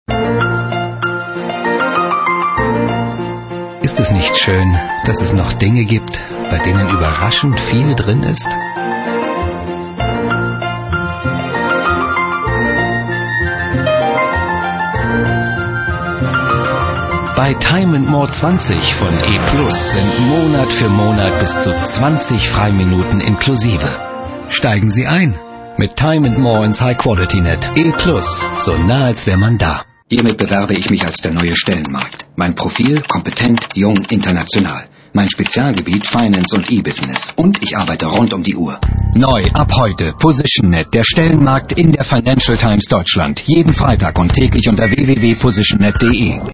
deutscher Synchronsprecher.
Kein Dialekt
Sprechprobe: Werbung (Muttersprache):